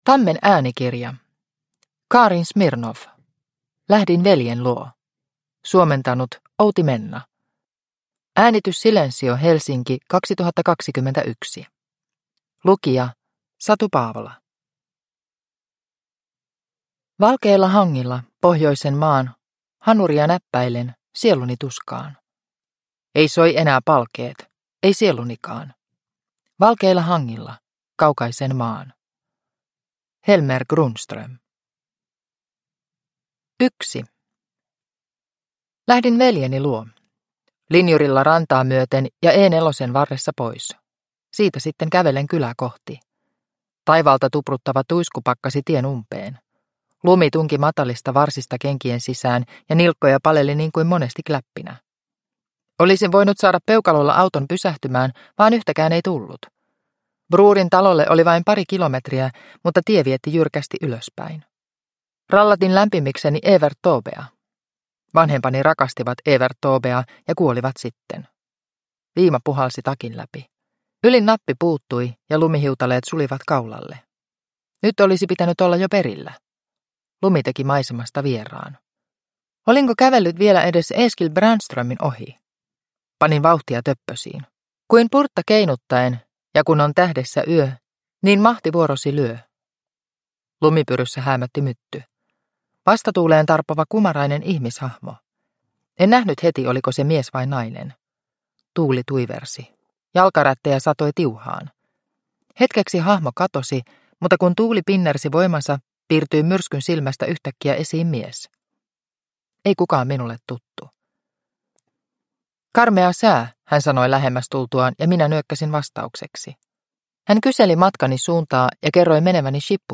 Lähdin veljen luo – Ljudbok – Laddas ner